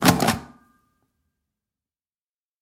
Звуки паспорта
Звук: поставили штамп в паспорт